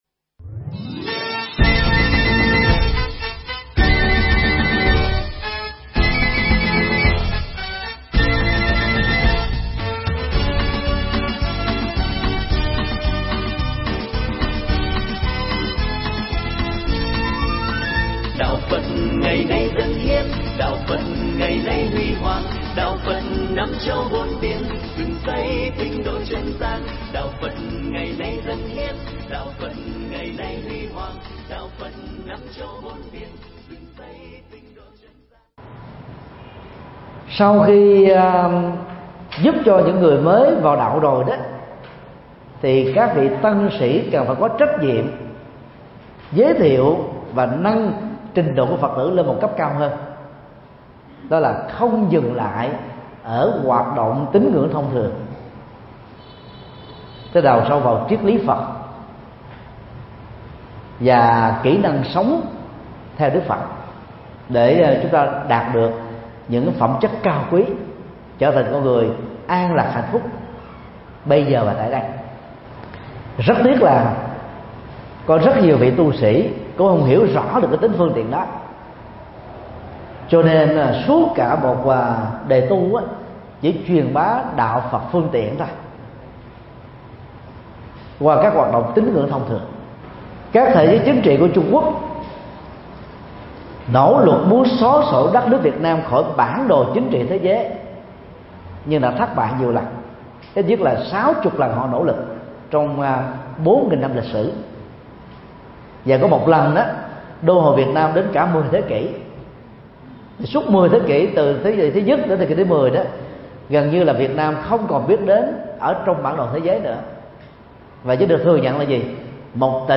Mp3 Pháp Thoại Đạo Trí Tuệ và Tuệ Giải Thoát – Thượng Tọa Thích Nhật Từ giảng tại chùa Ấn Quang (243 Sư Vạn Hạnh, quận 10, HCM) ngày 25 tháng 12 năm 2016